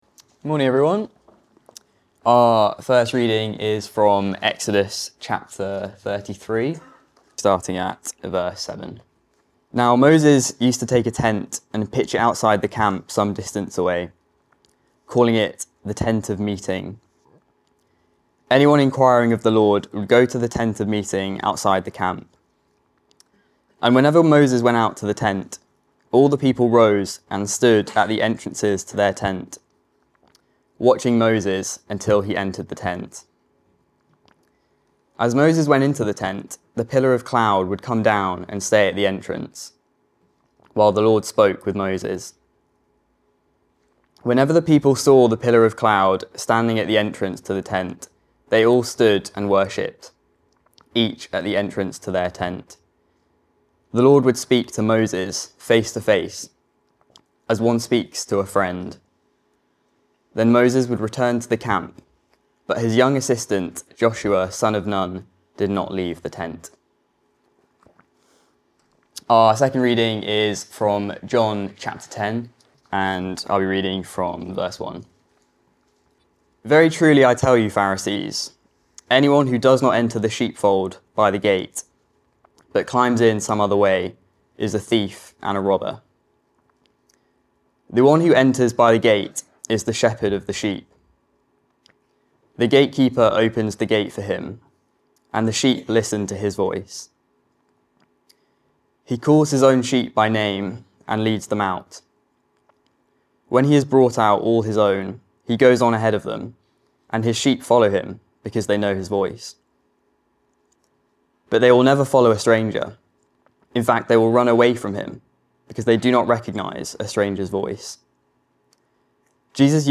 Weekly talks from Christ Church Balham's Sunday service